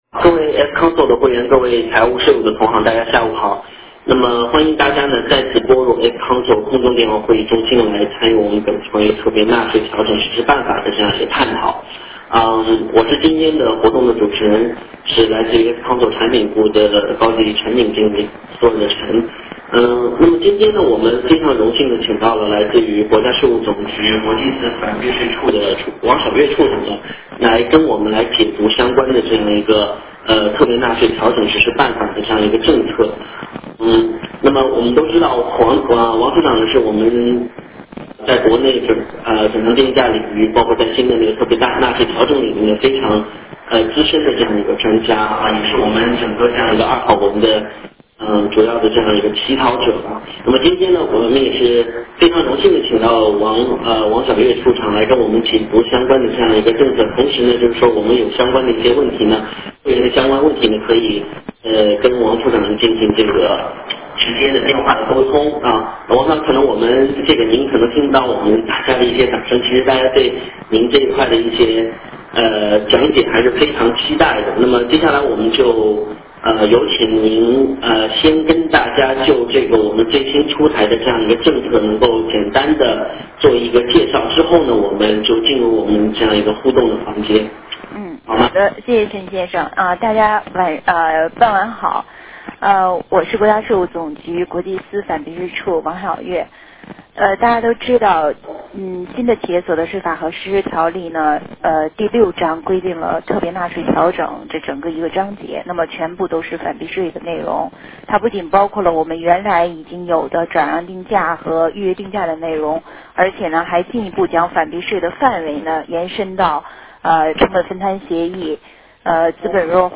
电话会议
活动安排： 时间： 2009年3月26日下午17:00-18:00 特邀嘉宾：国家税务总局国际司反避税处处长王晓悦 费用： F-Council会员免费，非会员1000元/人 形式： F-Council将自己对于《企业年度关联业务往来报告表》、同期资料准备、APA、成本分摊、资本弱化等转让定价问题的操作难点、企业困难、个人理解和建议与反避税处官员进行互动交流。